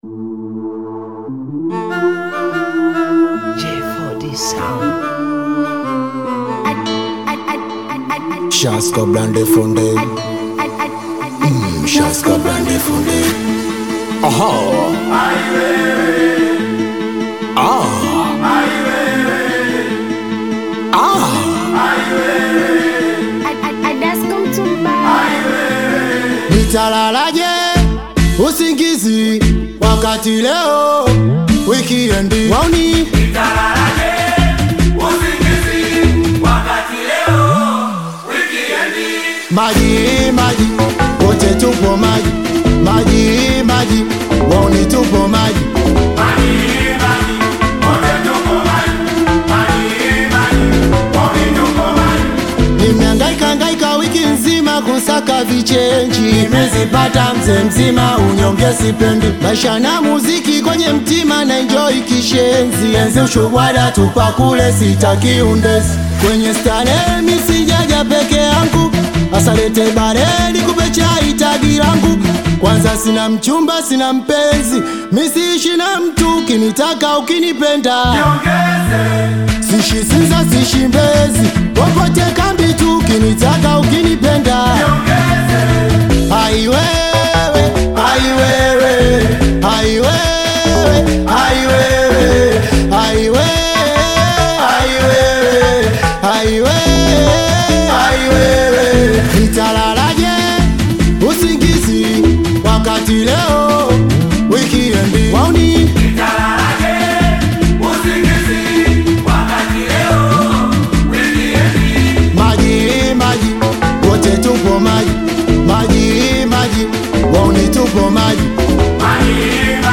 Singeli You may also like